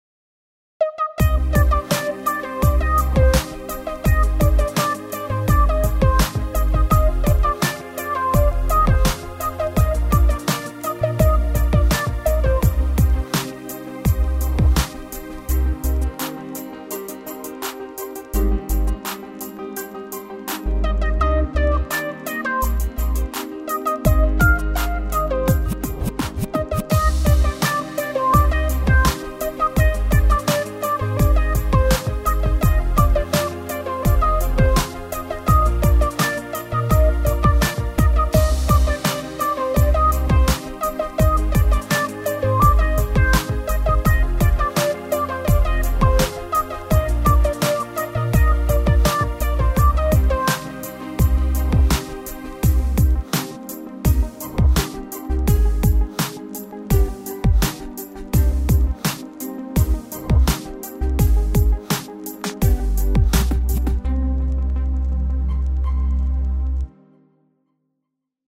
Urbaner, cooler, sanfter Beat.